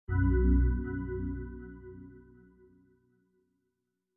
organic-chime_24810.mp3